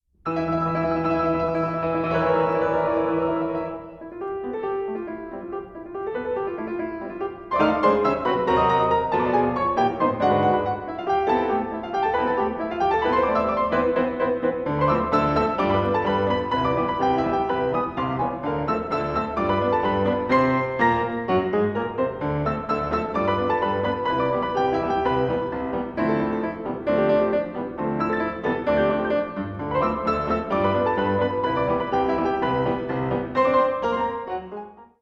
four hands at one and two pianos